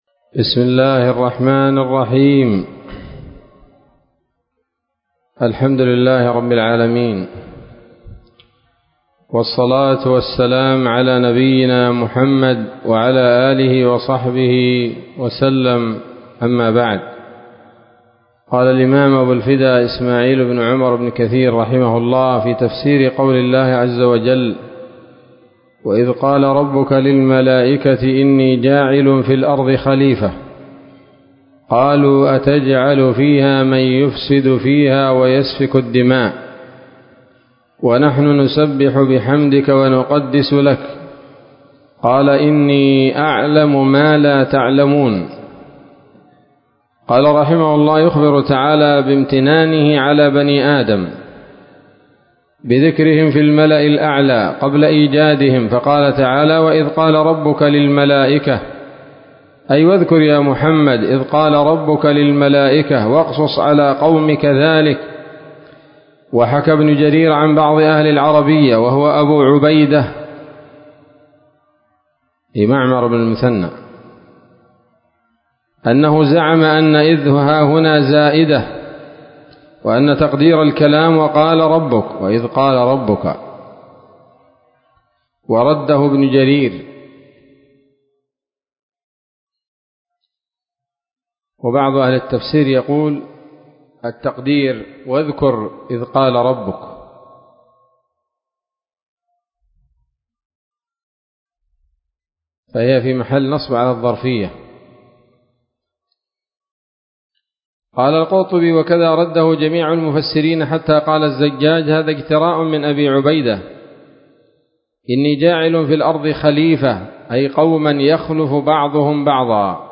الدرس الثالث والثلاثون من سورة البقرة من تفسير ابن كثير رحمه الله تعالى